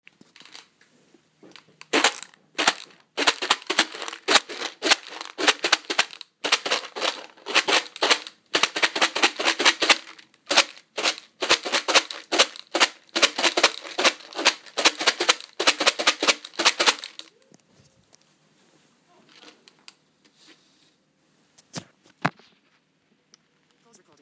Food percussion: Guess the instrument